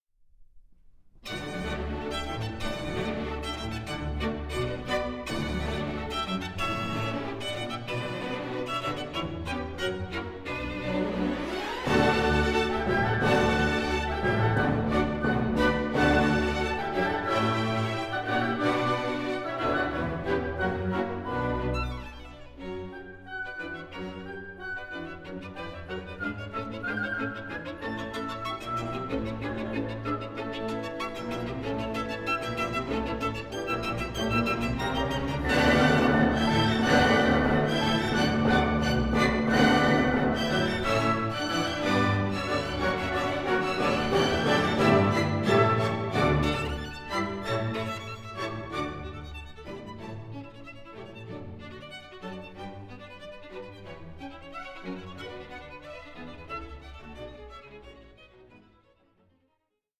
Allegro giocoso, ma non troppo vivace –Poco più presto 8.07